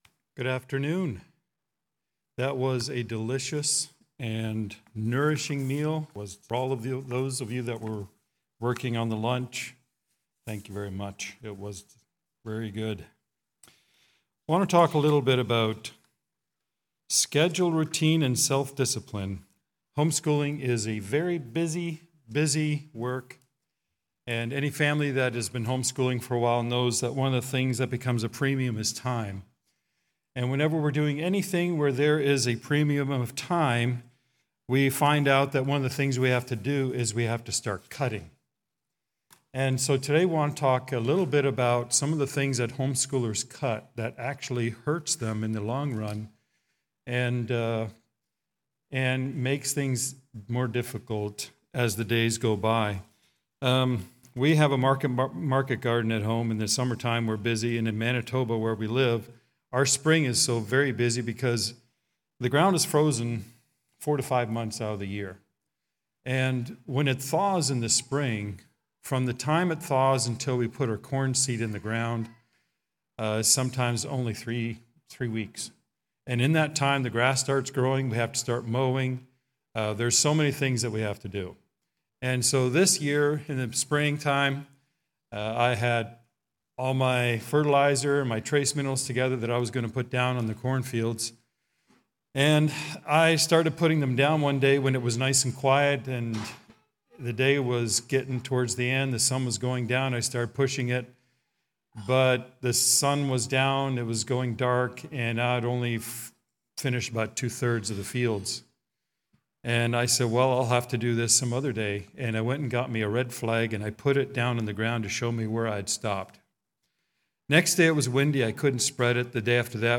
2025 CLE Homeschool Conference